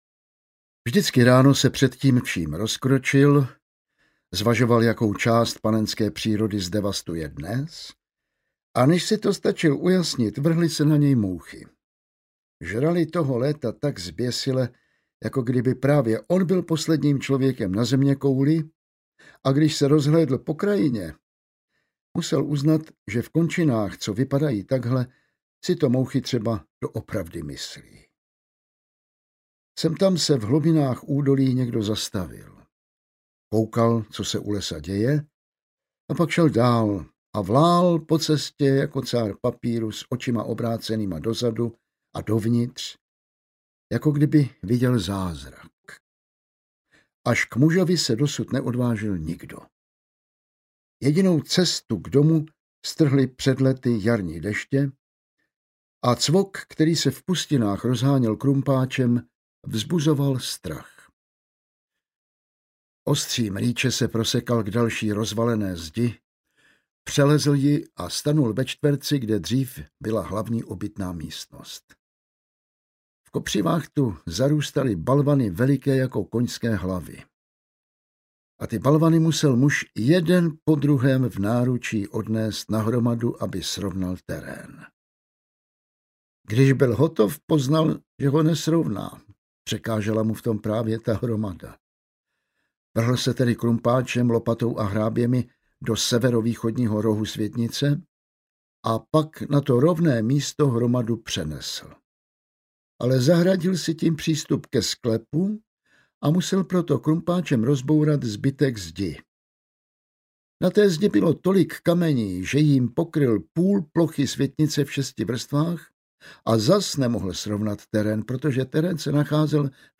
Audiobook
Read: Jan Vlasák